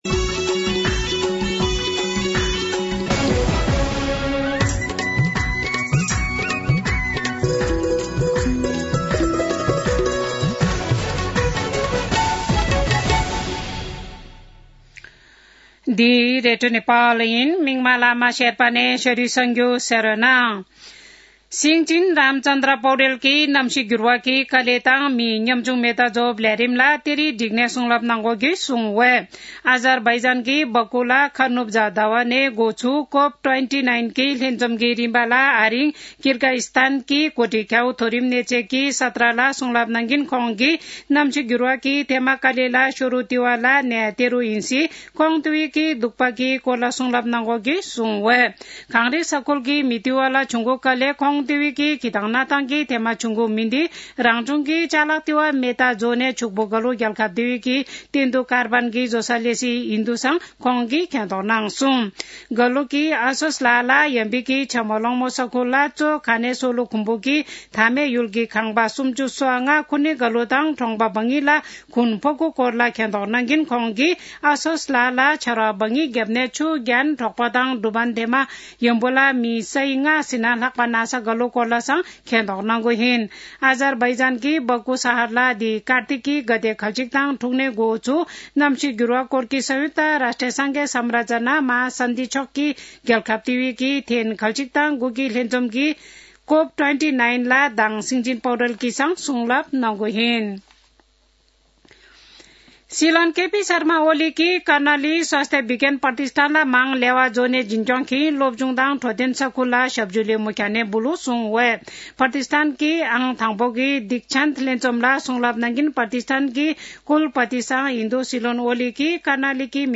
शेर्पा भाषाको समाचार : २९ कार्तिक , २०८१
Sherpa-News-07-28.mp3